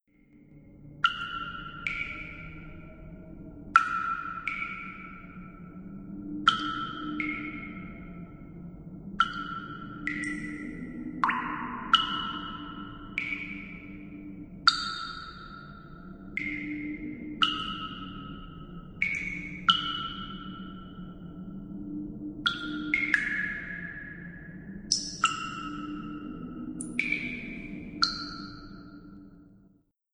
Halloween-DungeonWaterDripping.wav